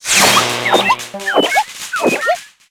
RobosaMalfunction.ogg